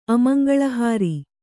♪ amaŋgaḷahāri